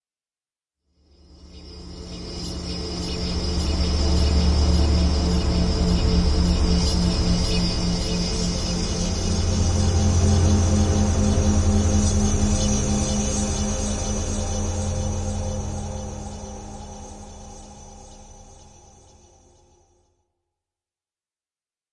描述：用vst仪器制作
Tag: 未来 无人机 驱动器 背景 隆隆声 黑暗 冲动 效果 FX 急诊室 悬停 发动机 飞船 氛围 完善的设计 未来 空间 科幻 电子 音景 环境 噪音 能源 飞船 大气